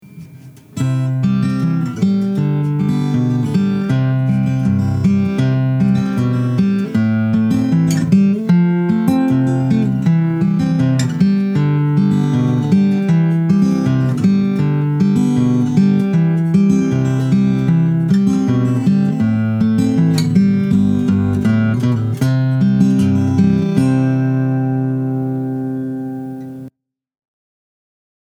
Finger style
This is a finger-style guitar made in partial fulfilment of the requirements for a Master Luthier certificate at Galloup School of Guitar Building and Repair, in Big Rapids, MI. It is a Jumbo-style body made of Mahogany with a Mahogany neck, zircote fretboard, bridge, and peghead overlay.
The soundboard and back are fully tuned using methods taught at the Galloup School. It has excellent balance, tone and projection.
CONO-Fingerstyle.mp3